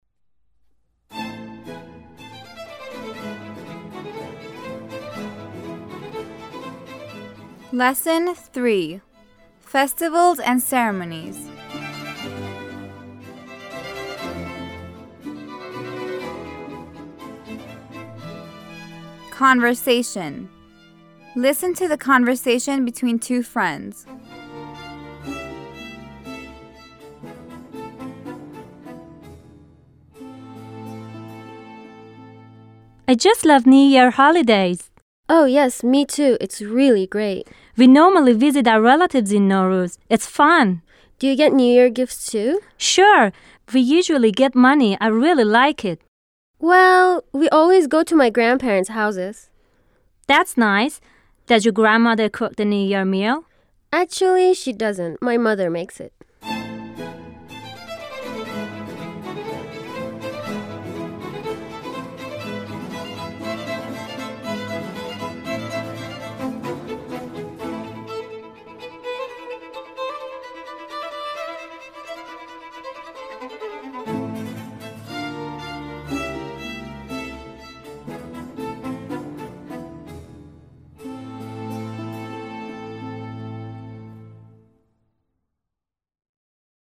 9-L3-Conversation
9-L3-Conversation.mp3